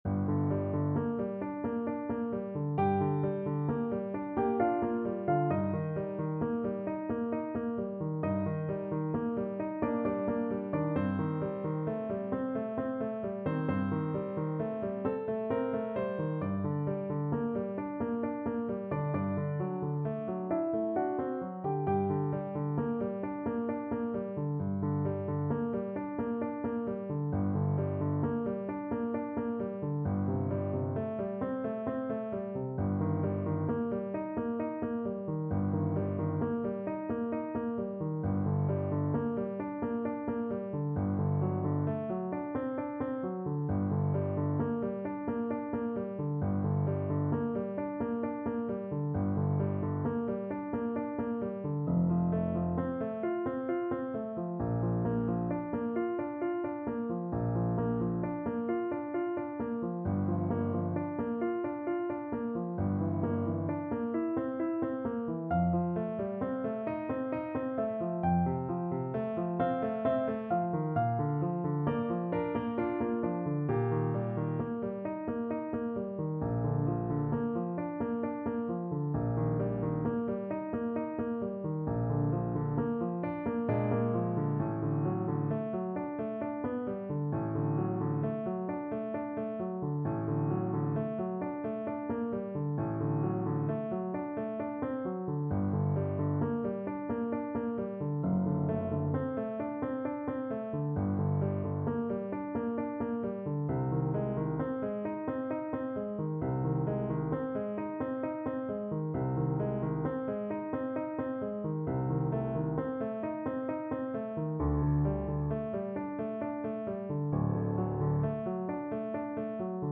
~ = 100 =66 Andante
3/4 (View more 3/4 Music)